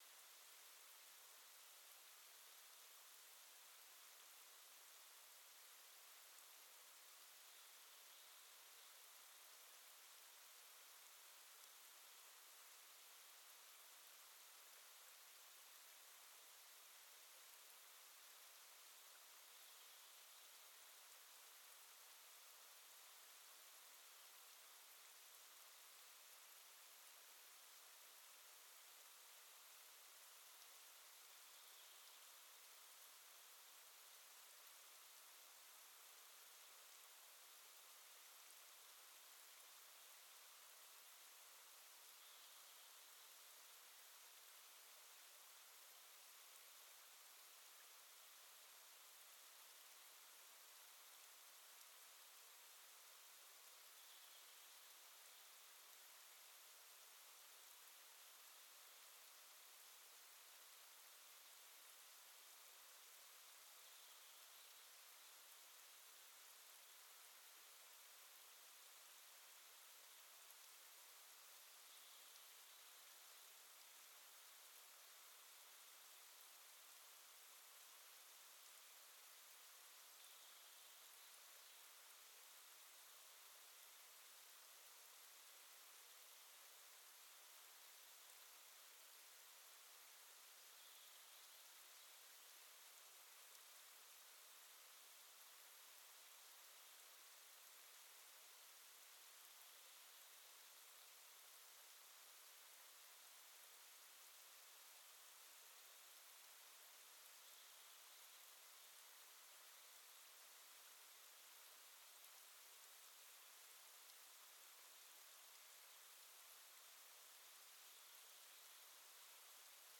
Quellrauschen16000.mp3